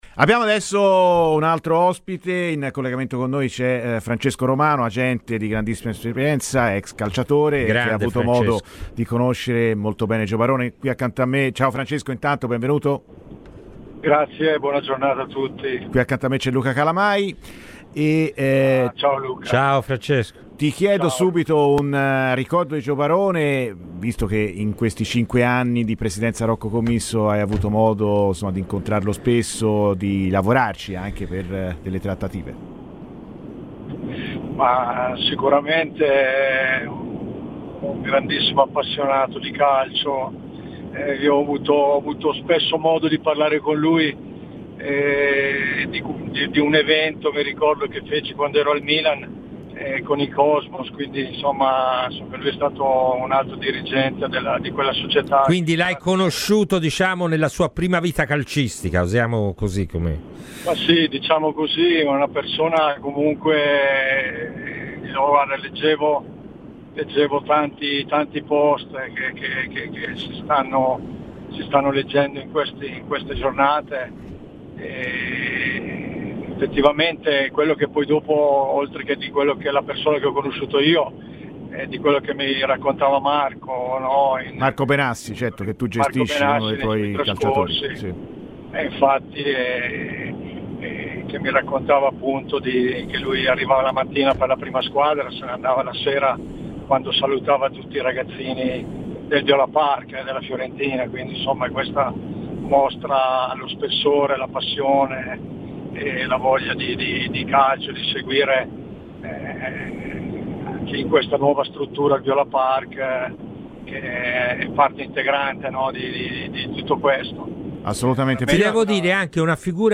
Ascolta il podcast per l'intervento integrale.